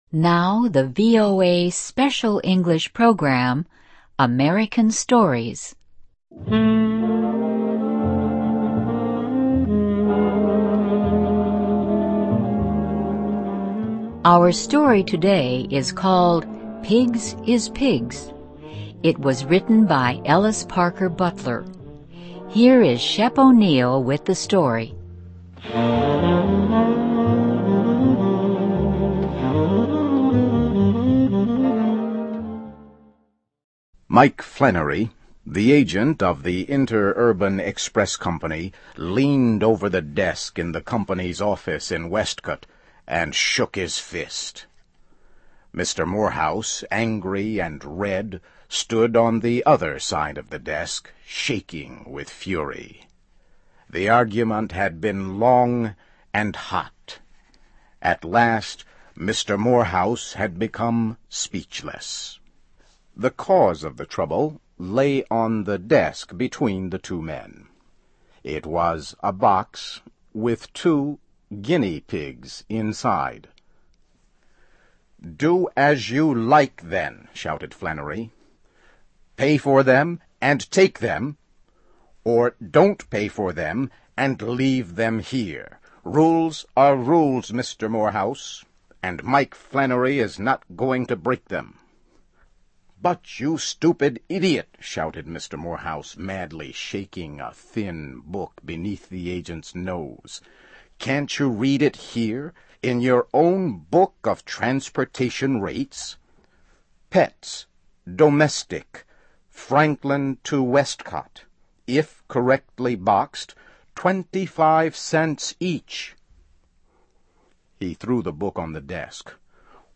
(MUSIC) Our story today is called ��Pigs is Pigs.�� It was written by Ellis Parker Butler.